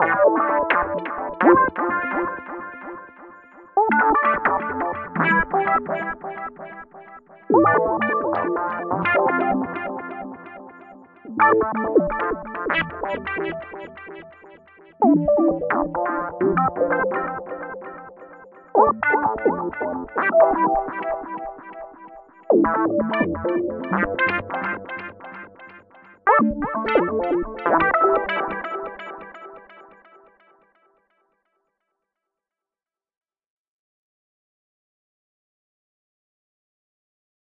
Tag: 合成器 现场 Ableton公司